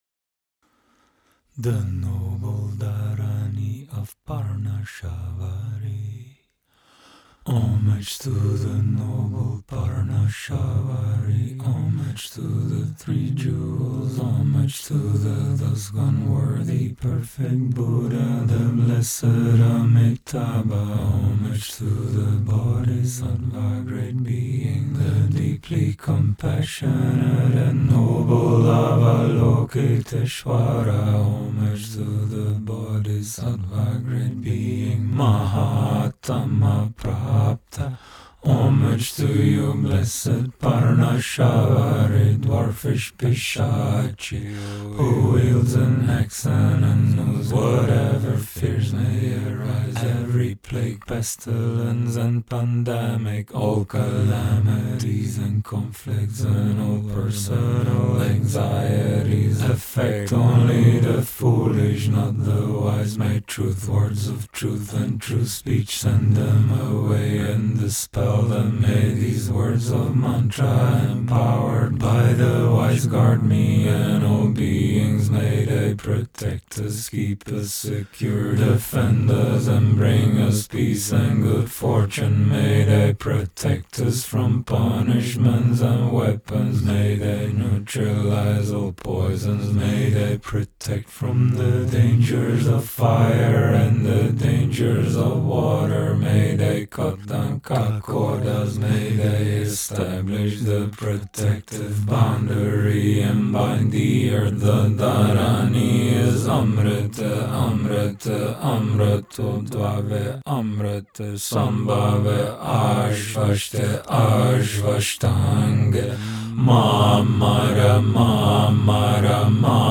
vocal and production